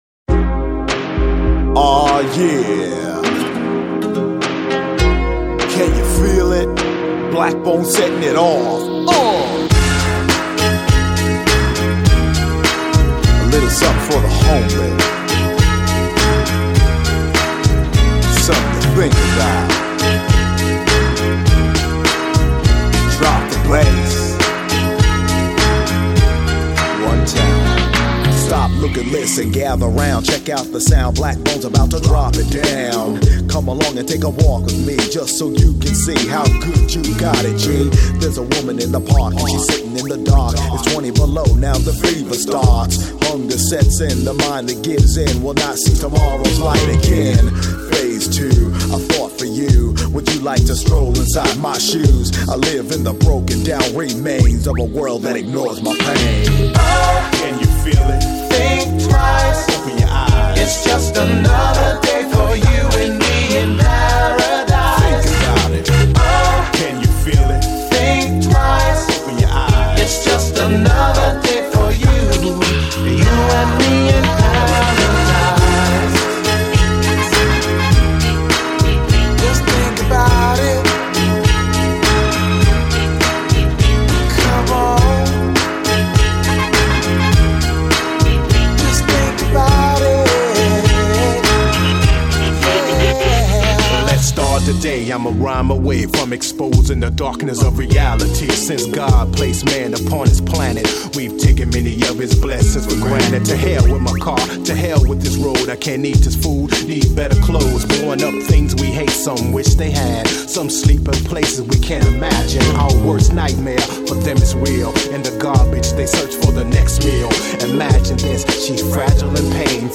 Жанр: Rap, Hip-Hop